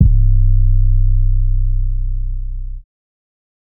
siz 808_1.wav